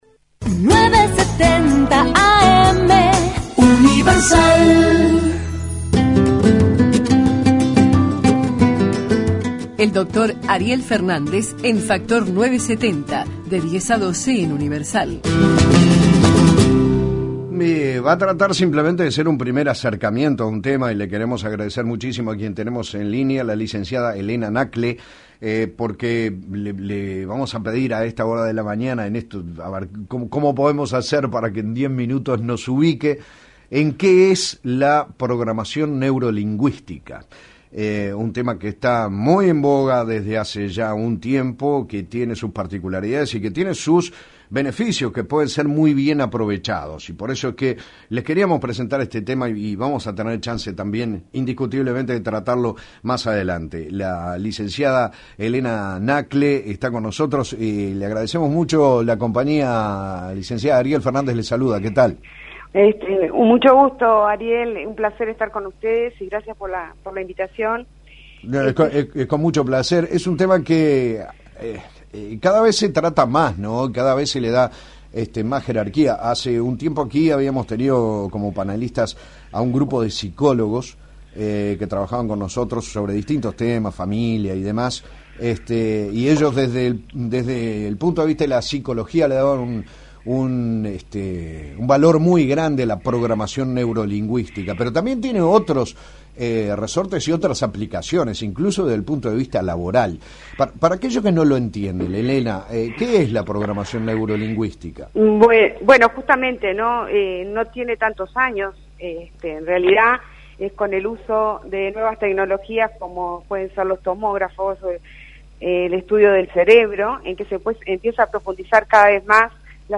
entrevista_universal.mp3